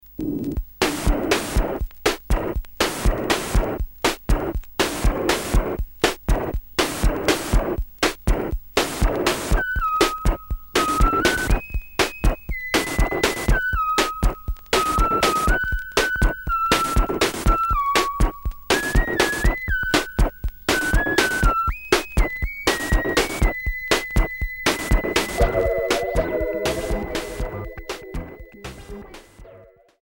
New wave expérimentale Premier 45t retour à l'accueil